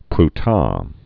(pr-tä)